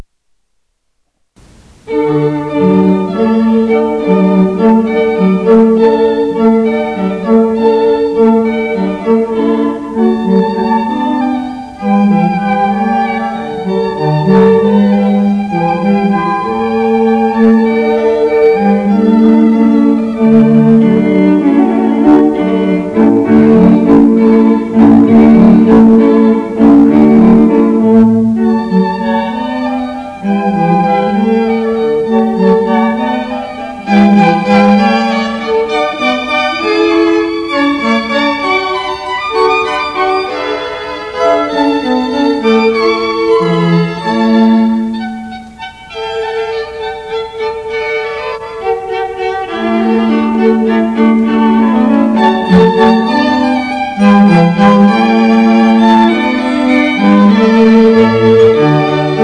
Serenade String Quartet
Baroque Music Samples